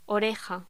Locución: Oreja
voz